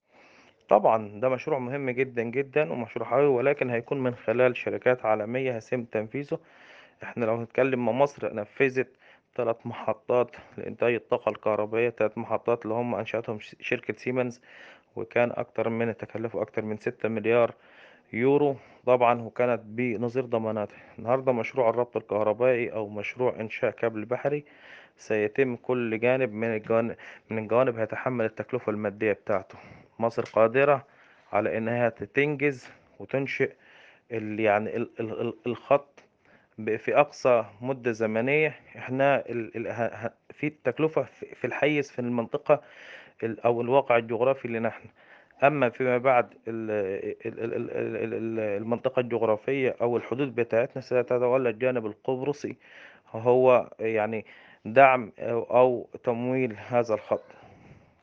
حوار
محلل اقتصادي ومتخصص في قطاع الكهرباء والطاقة